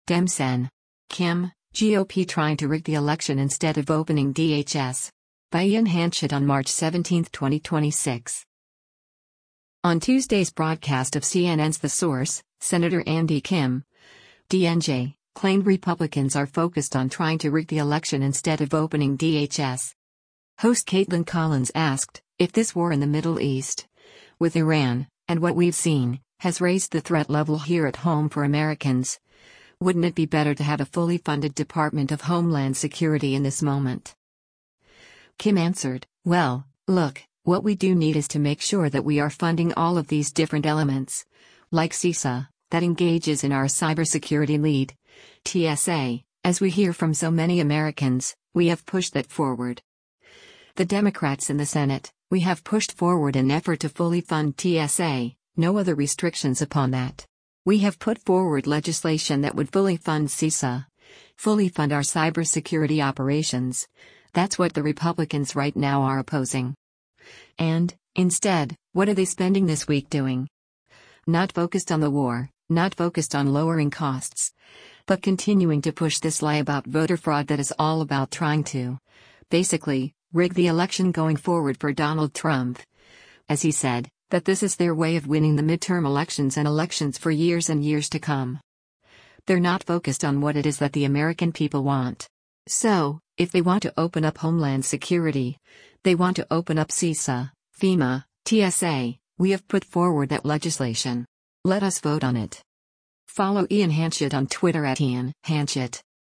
On Tuesday’s broadcast of CNN’s “The Source,” Sen. Andy Kim (D-NJ) claimed Republicans are focused on trying to “rig the election” instead of opening DHS.